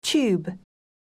듣기 영국 [tjuːb]